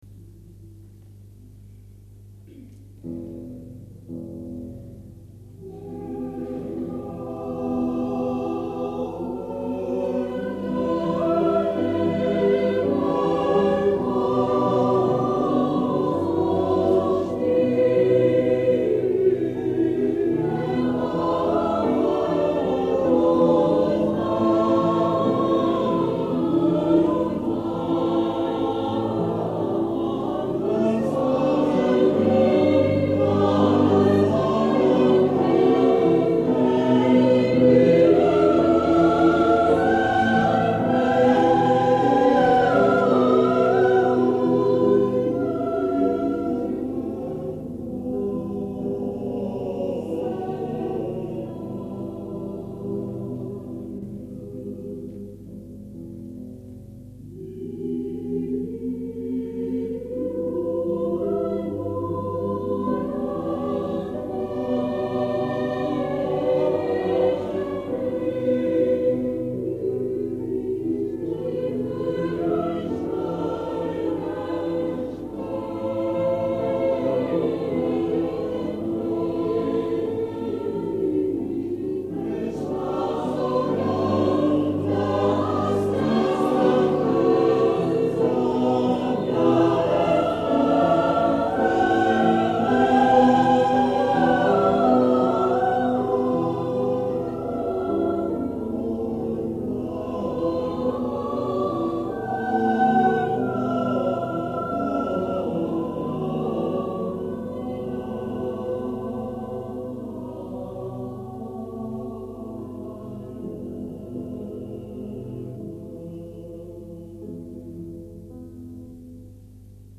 Concert du XXXe Anniversaire "A Coeur Joie Macon" 28 avril 1973
Cathedrale St-Vincent MACON
Extraits du Concert